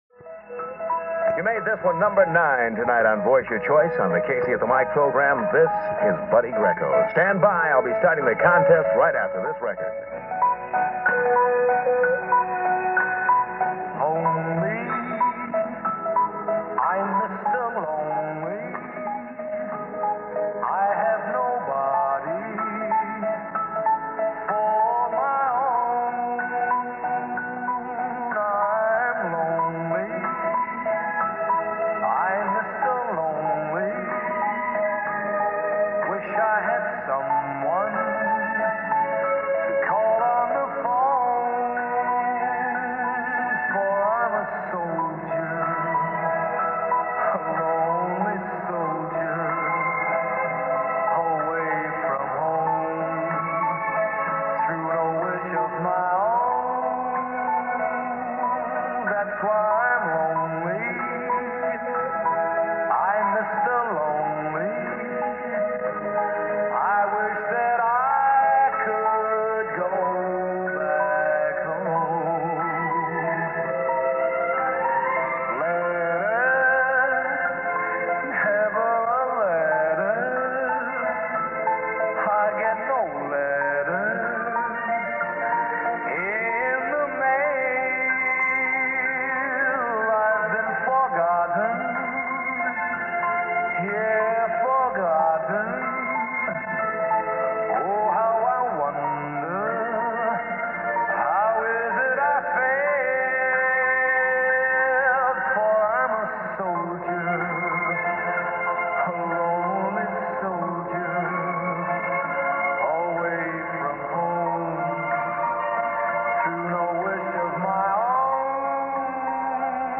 Click on the link here for Audio Player – Casey Kasem – KEWB-AM, San Francisco October 2, 1962